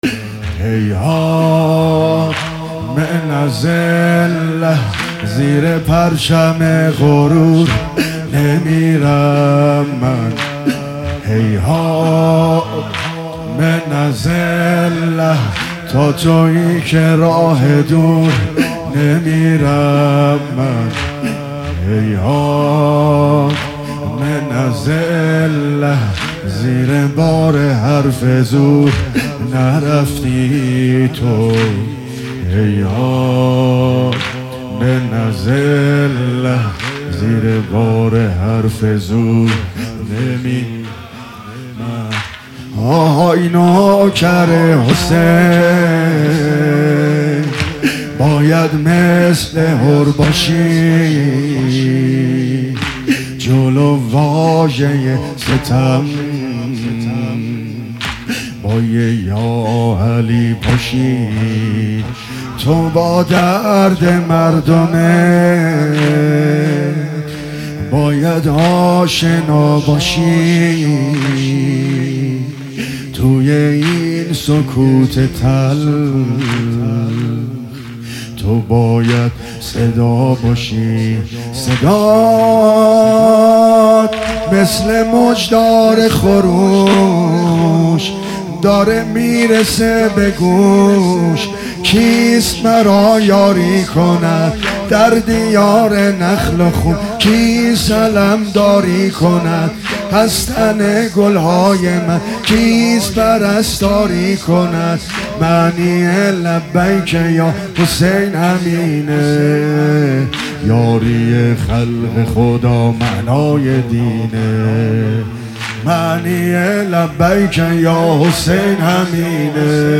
مداحی واحد